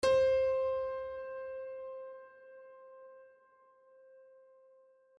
piano-sounds-dev
c4.mp3